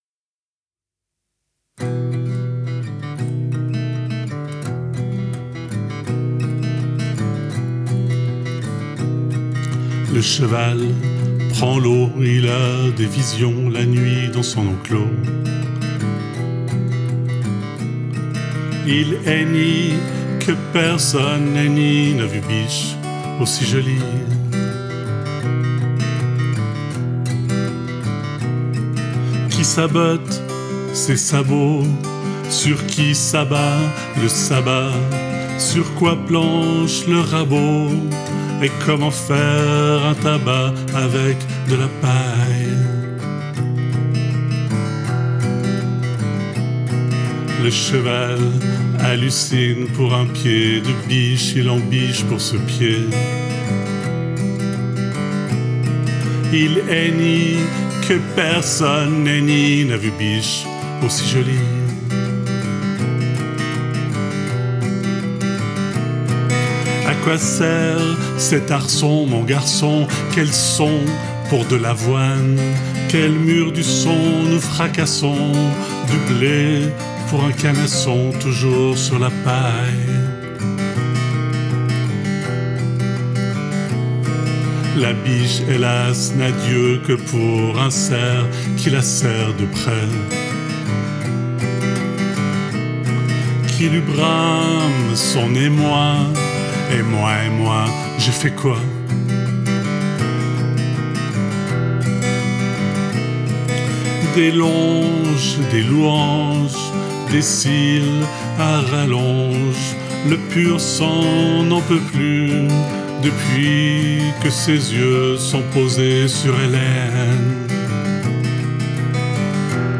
guitare, voix